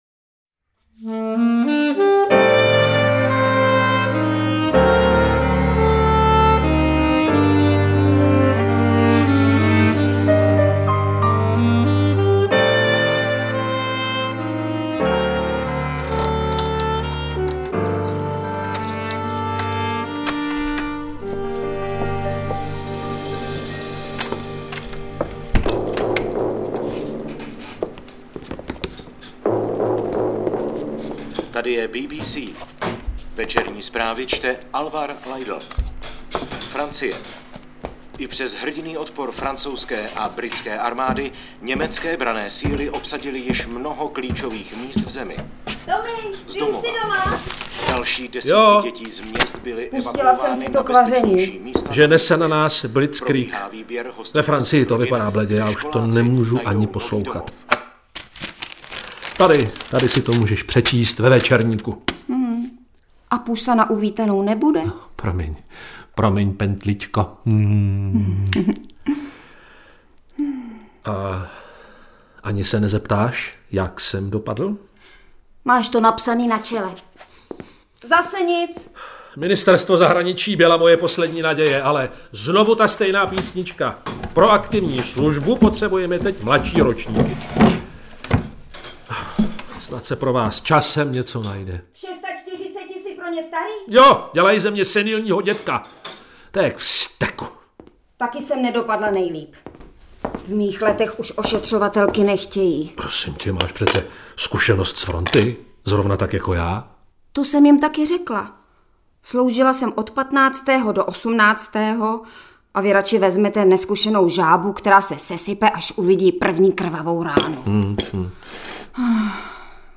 Interpreti:  Robert Jašków, Miroslava Pleštilová